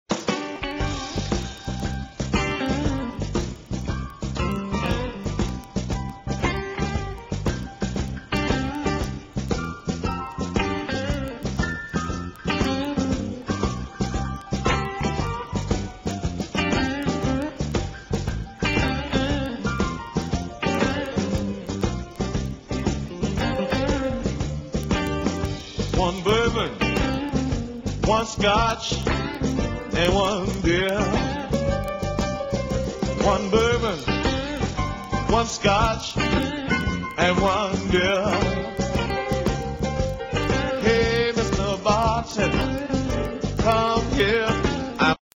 BLUES KARAOKE MUSIC CDs